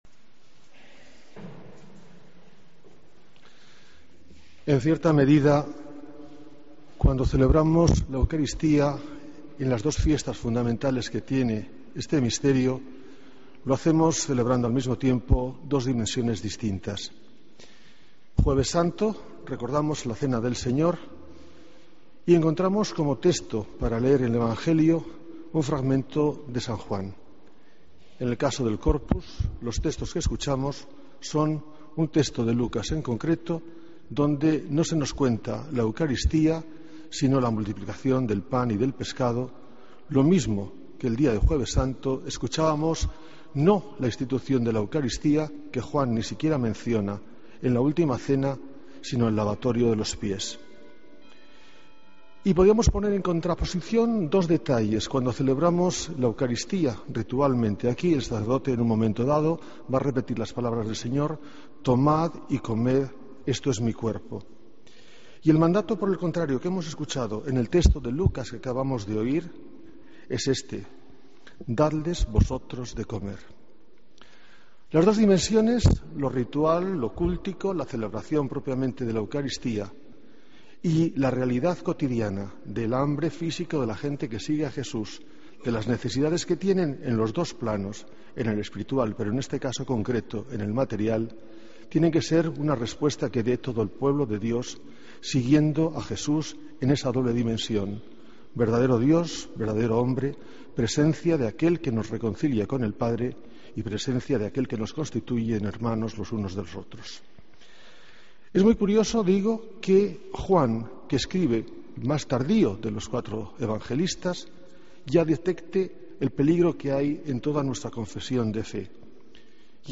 Homilía, domingo 2 de junio de 2013